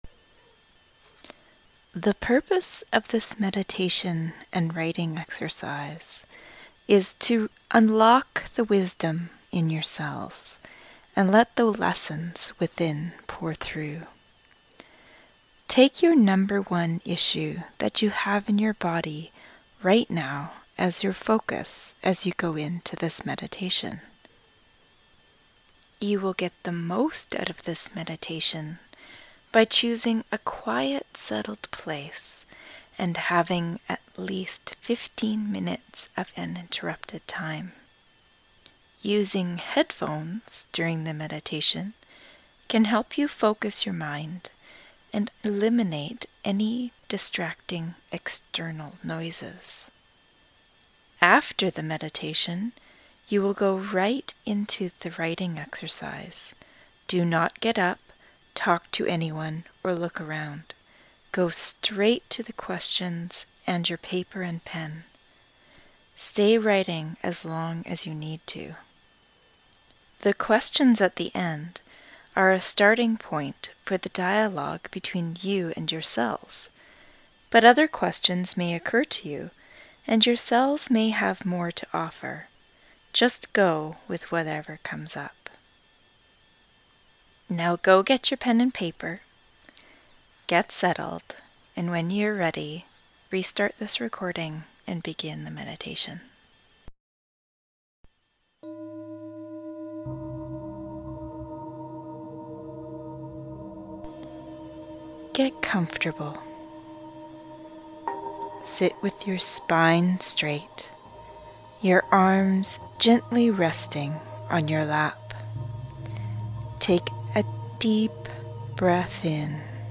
Writing to your Cells Meditation